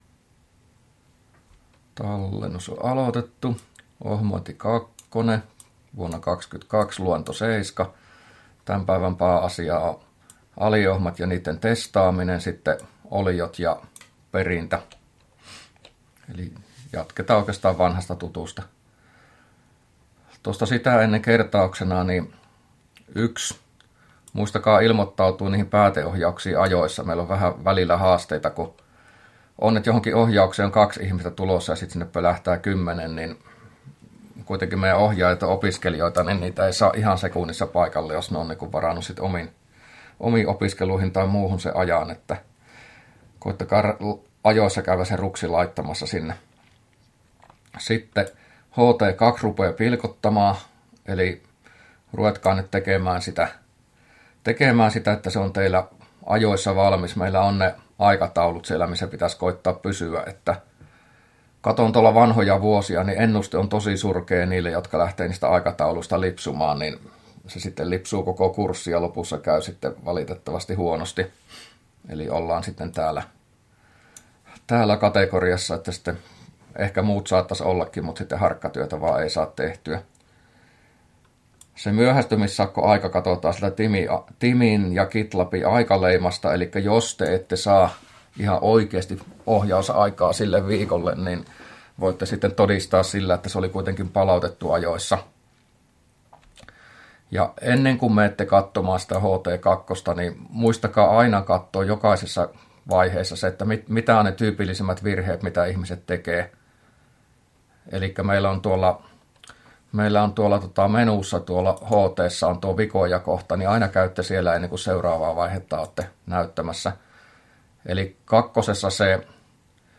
luento07a